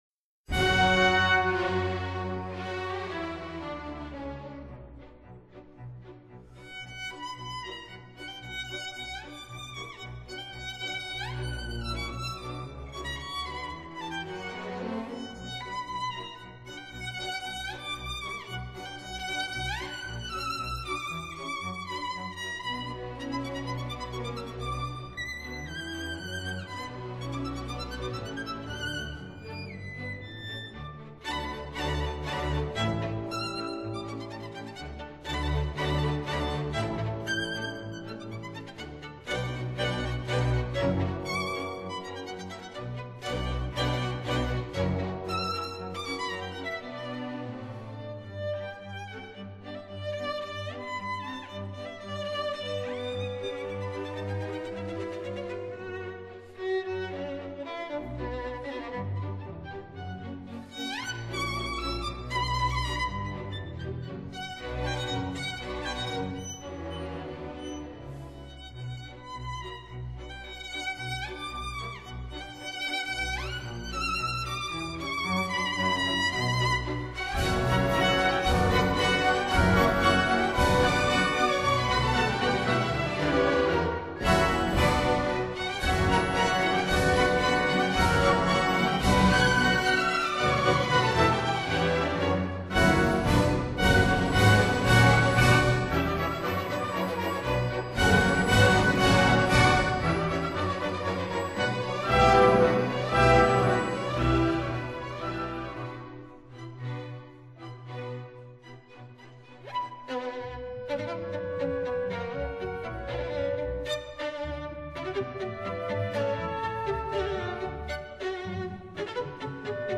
•(01) Violin Concerto No. 2 in B minor, Op. 32
violin